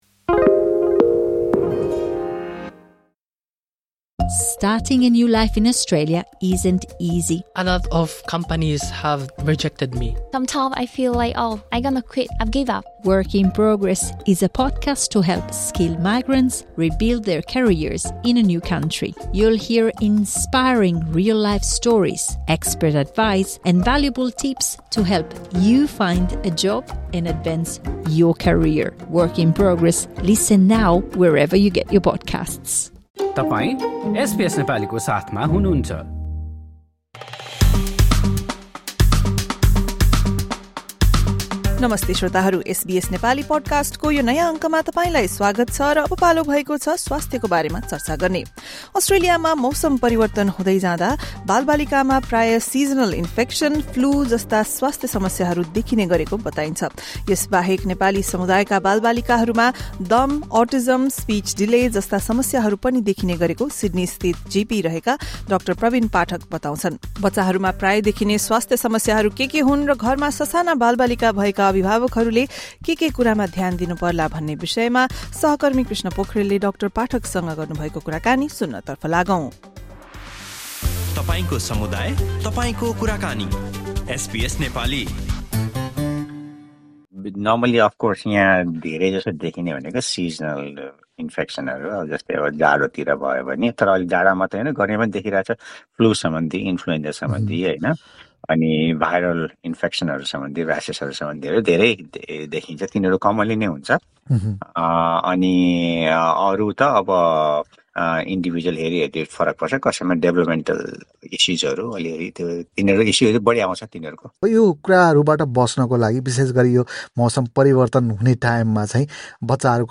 एसबीएस नेपालीले गरेको कुराकानी सुन्नुहोस्।